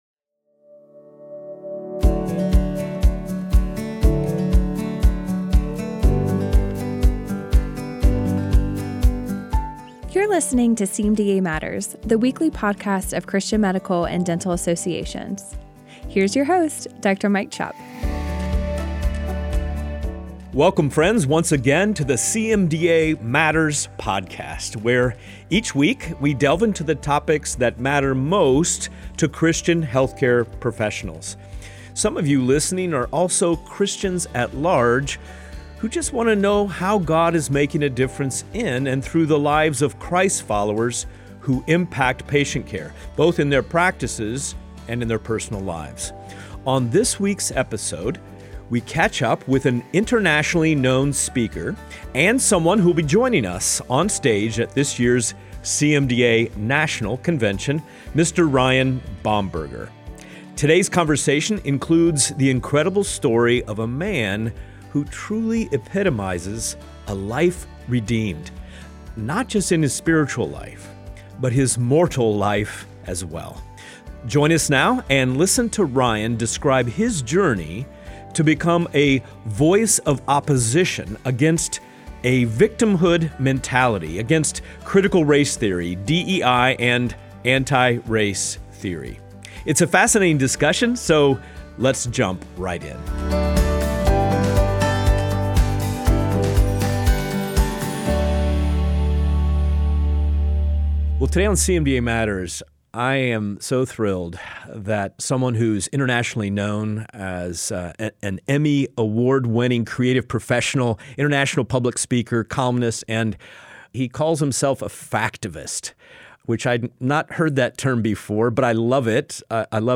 It’s a fascinating discussion.